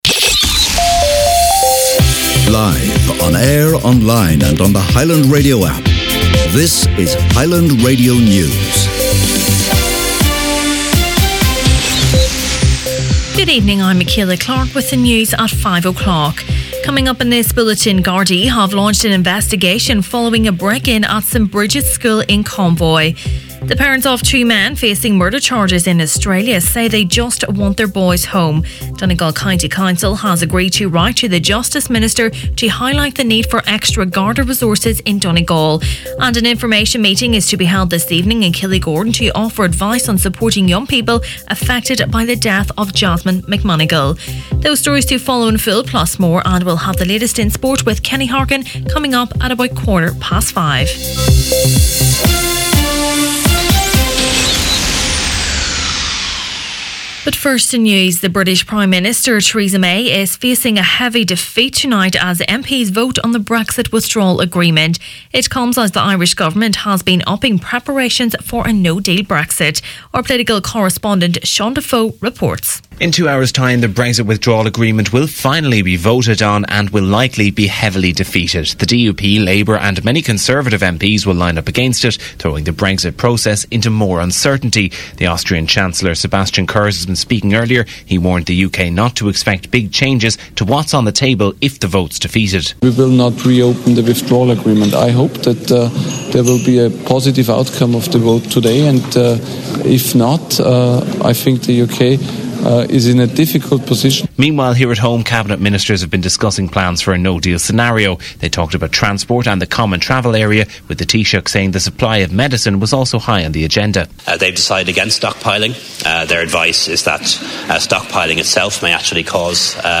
Main Evening News, Sport and Obituaries Tuesday January 15th